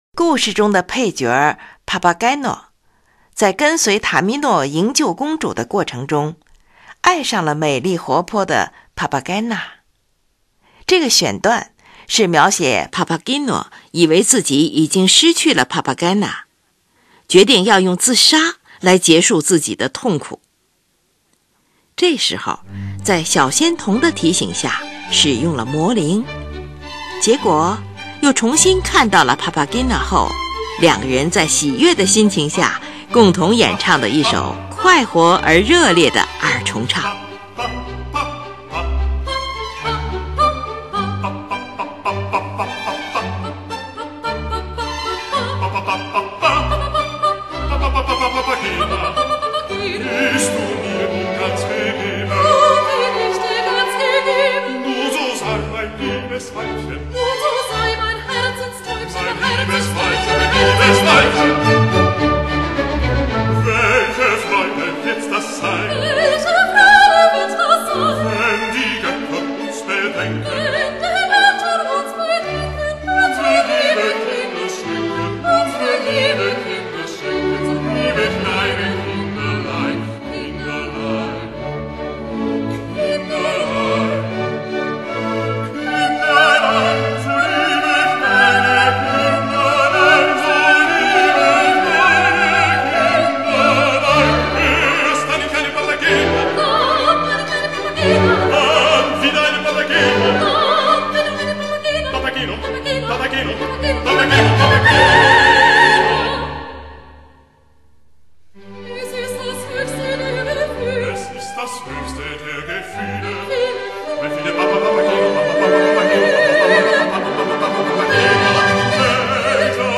歌剧，大歌剧，喜歌剧，二重唱。